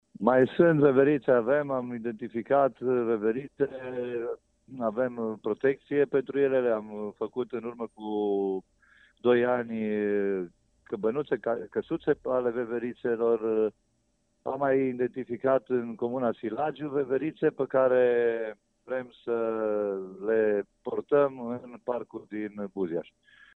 Primarul Sorin Munteanu spune că au fost identificate câteva locuri de unde să fie aduse noi veverițe.
insert-2-material-primar-buzias.mp3